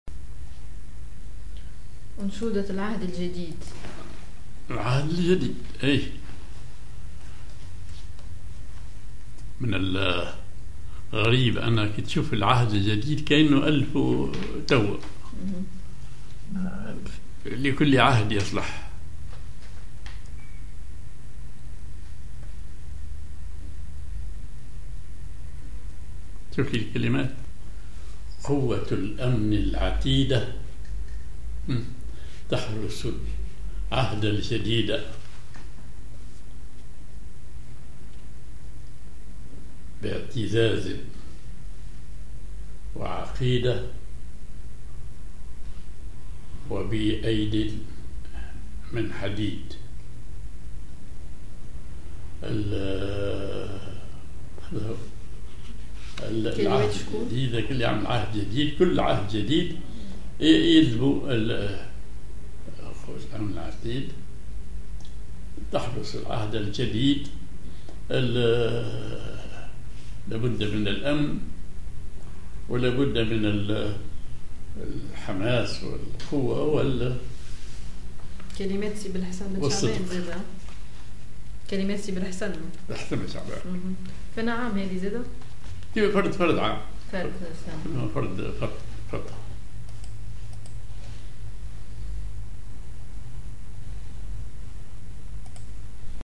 Maqam ar حجازكار
genre بشرف